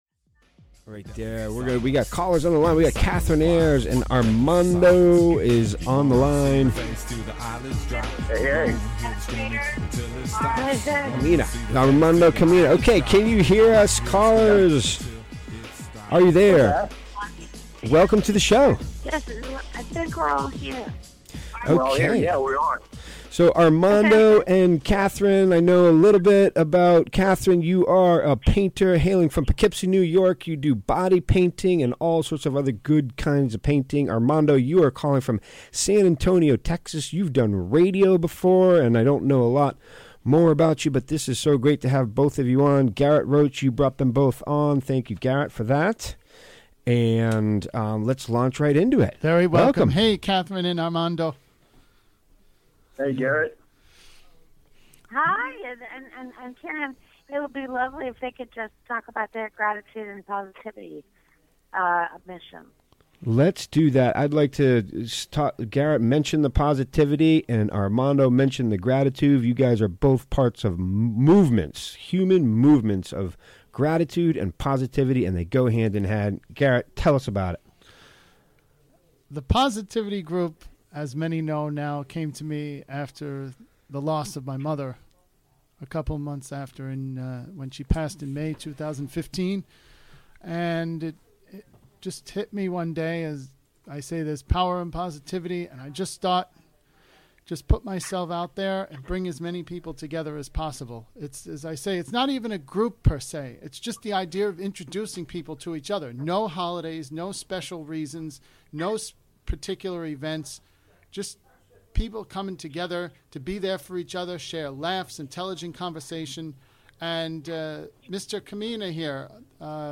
Recorded live on the WGXC Afternoon show on May 7, 2018.